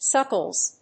suckles.mp3